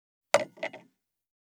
246,グラス,コップ,工具,小物,雑貨,コトン,トン,ゴト,ポン,ガシャン,
コップ効果音厨房/台所/レストラン/kitchen物を置く食器